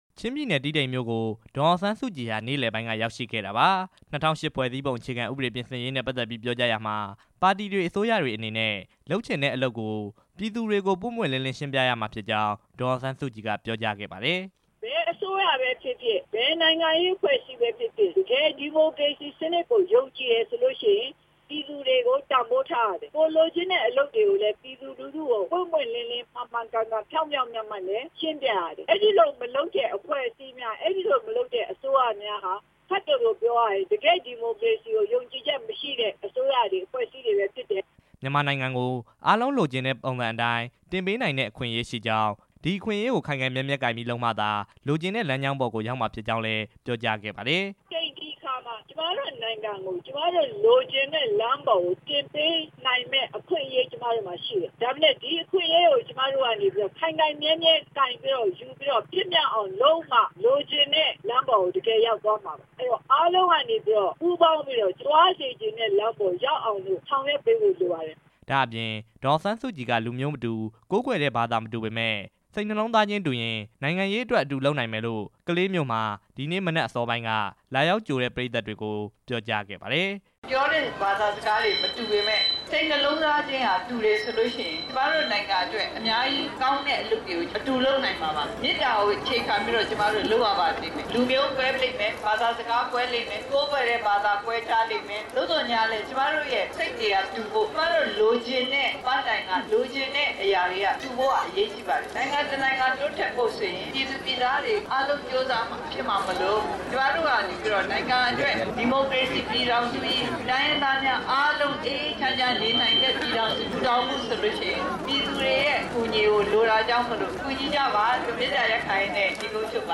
ချင်းပြည်နယ် တီးတိန်မြို့မှာ ၂၀၀၈ ဖွဲ့စည်းပုံ အခြေခံဥပဒေ ပြင်ဆင်ရေးနဲ့ ပတ်သက်ပြီး ဒေသခံတွေ ကို ပြောကြားစဉ် ထည့်သွင်းပြောခဲ့တာပါ။